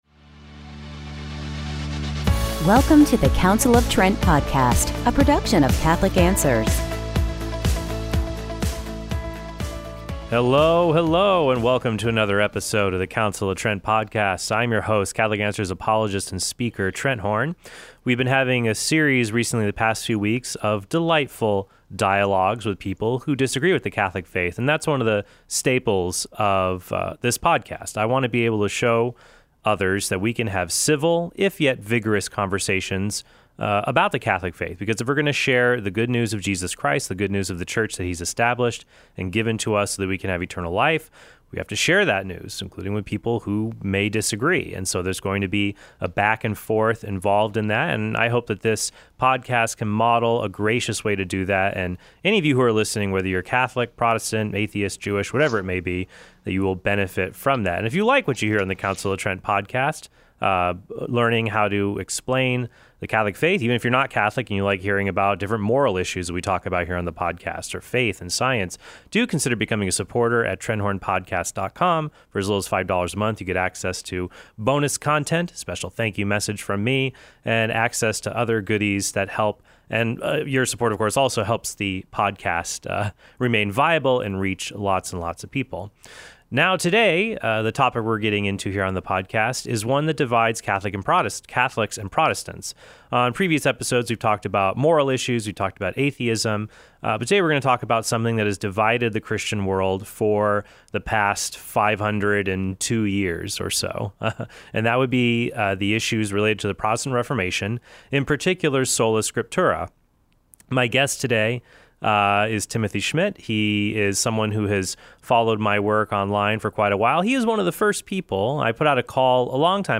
DIALOGUE: Is Sola Scriptura Biblical?